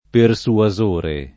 persuasore [ per S ua @1 re ]